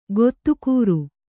♪ gottukūru